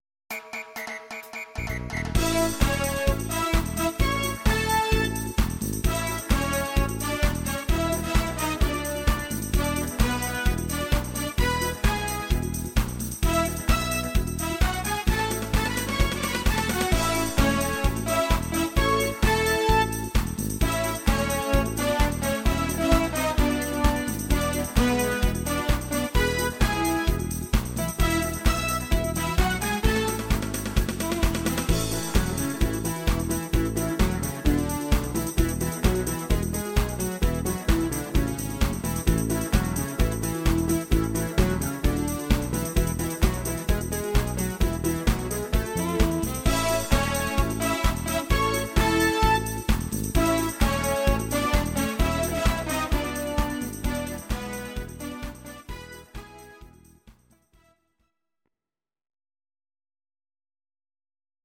Audio Recordings based on Midi-files
Pop, German, 1990s